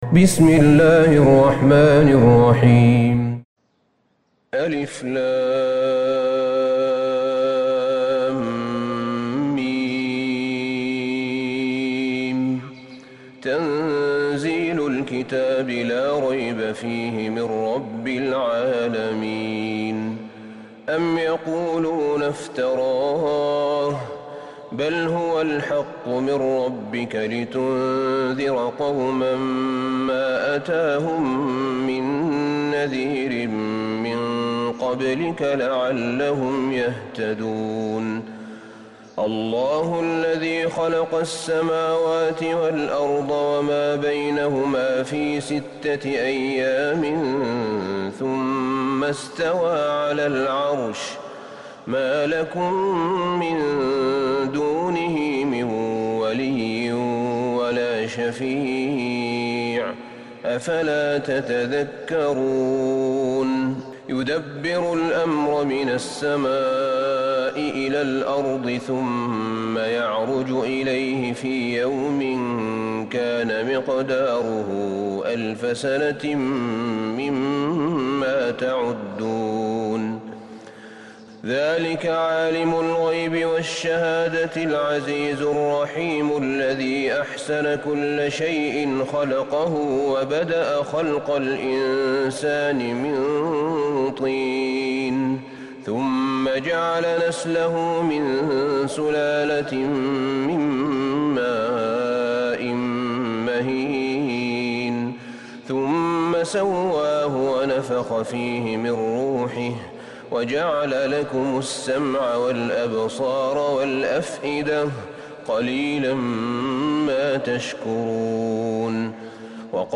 سورة السجدة Surat AsSajdah > مصحف الشيخ أحمد بن طالب بن حميد من الحرم النبوي > المصحف - تلاوات الحرمين